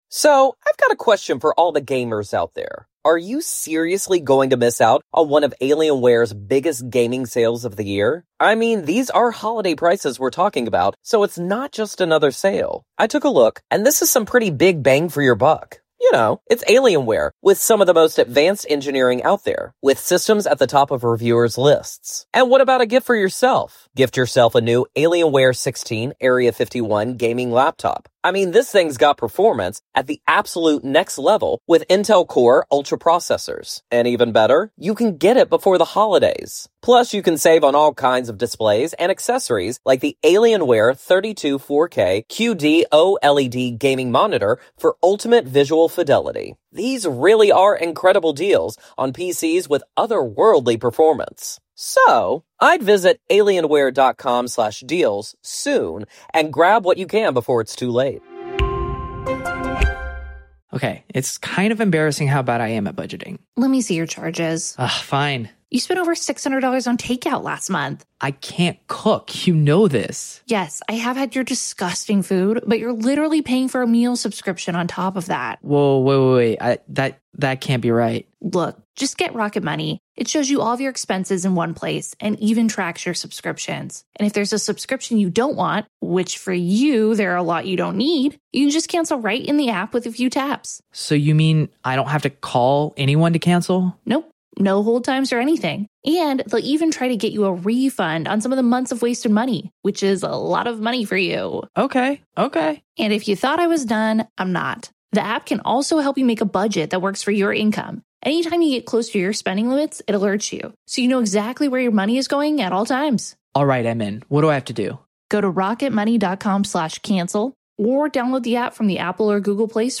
All that, plus YOUR phone calls.